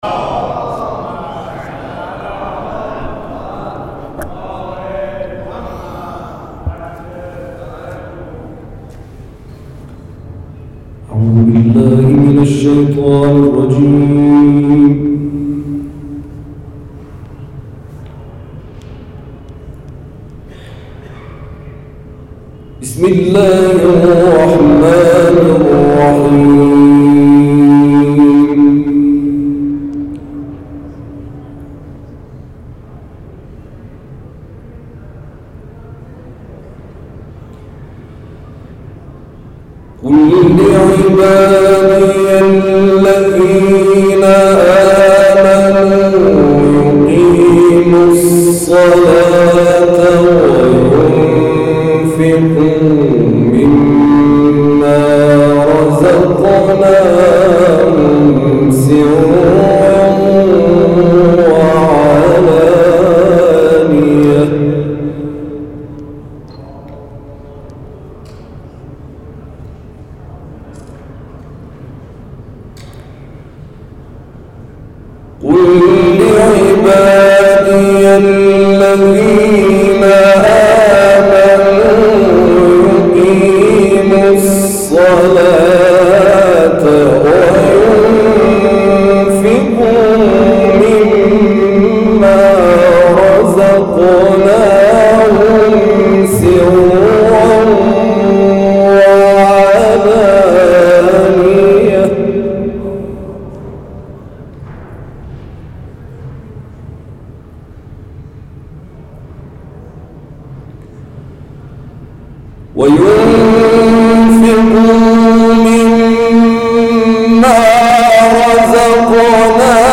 در مجلس ترحیم «محمدتقی مروت» بنیانگذار هنر تلاوت قرآن در ایران آیاتی از سوره مبارکه «ابراهیم» را تلاوت کرد.
در مسجد امام جعفر صادق(ع) واقع در میدان فلسطین تهران دایر شد.
از قاریان ممتاز کشور در این مراسم تلاوتی را از آیات 31 تا 41 سوره مبارکه ابراهیم اجرا کرد که می‌توانید در ادامه به آن گوش فرا دهید.